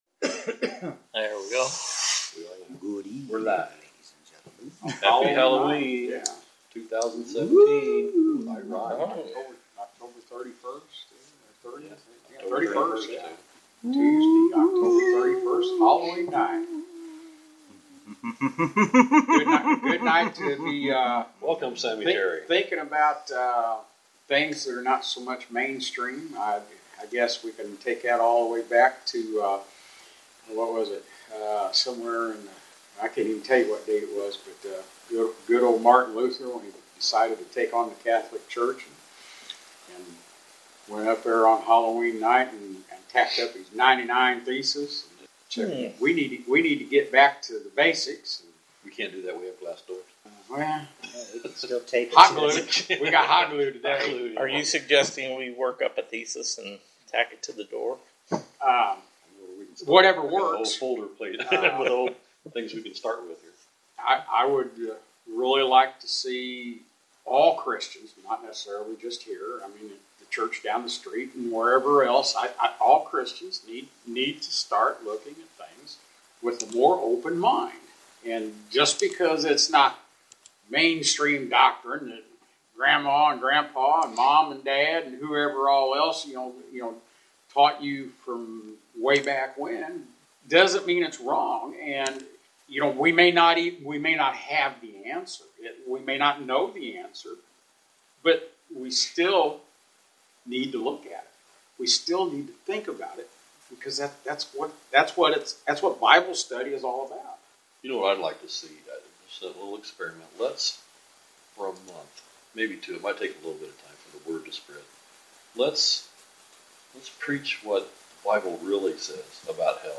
A discussion which opens with Martin Luther's famous act of defiance on Halloween of 1517 and ends in Utopia. Another of those free-wheeling sessions that touch on many of the principle ideas central to our study.